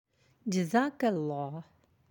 (Jazak Allah)